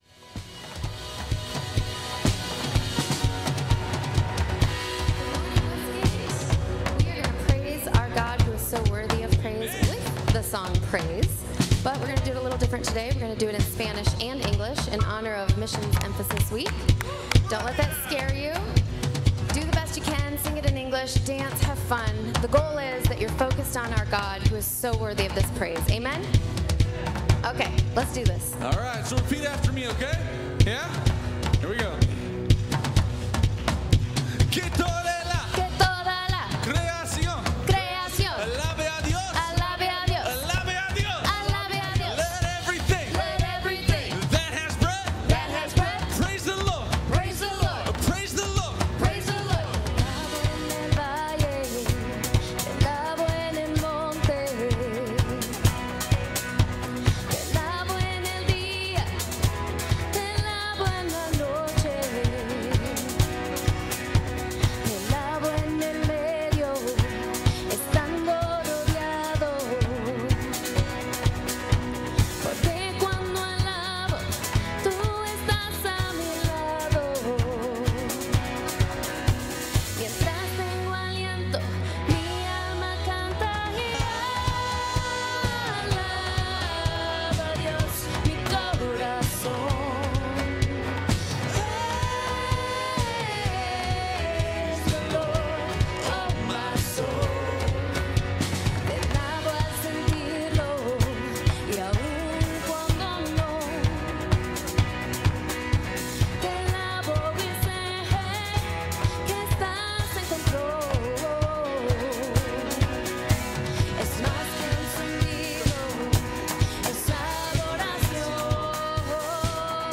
Westgate Chapel Sermons Missions Emphasis Week - Alliance World Tour Oct 13 2025 | 00:50:50 1x 00:00 / 00:50:50 Subscribe Share Apple Podcasts Overcast RSS Feed Share Link Embed